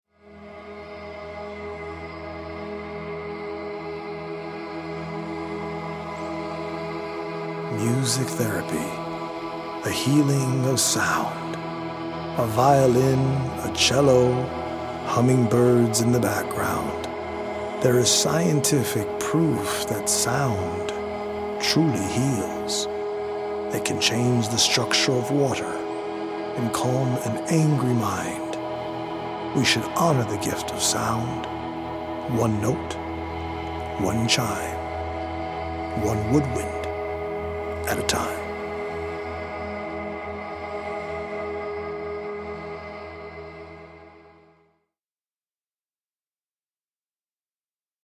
original poems written/performed by
healing Solfeggio frequency music
EDM producer